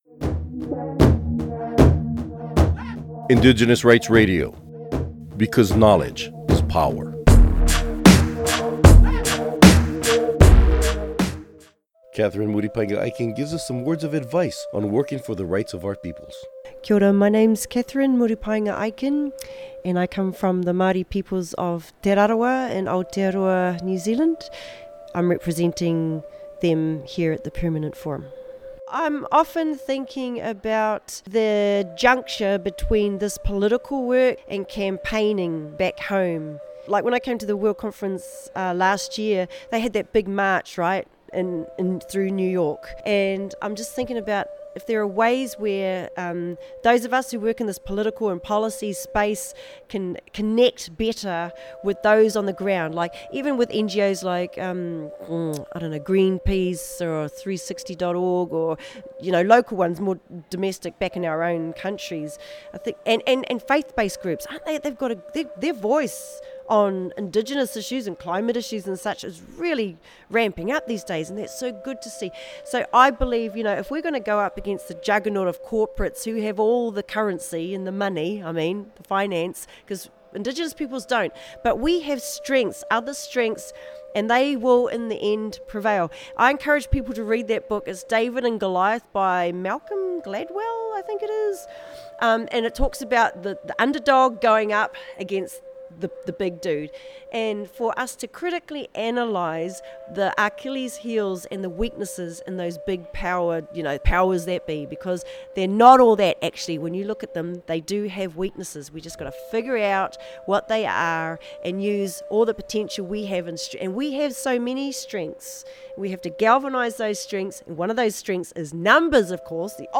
Recording Location: UNPFII 2015
Type: Interview